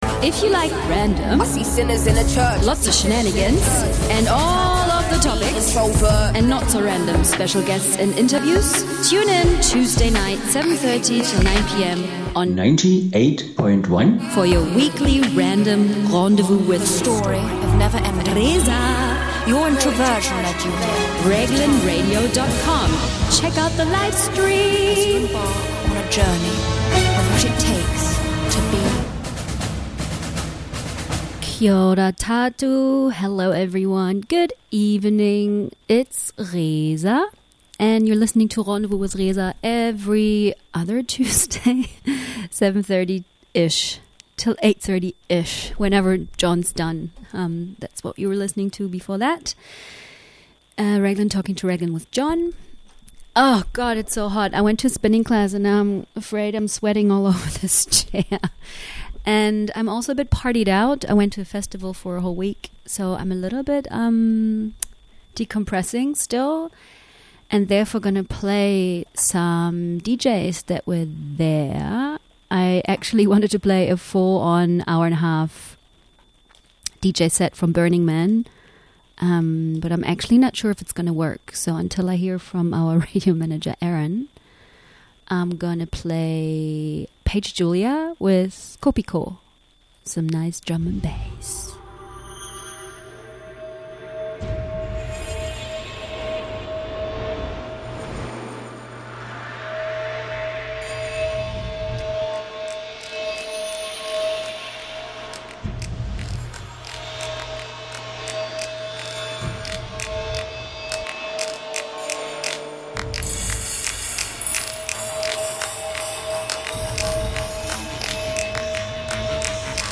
the Dub Step, Drum N Bass and EDM. So here is a DJ special.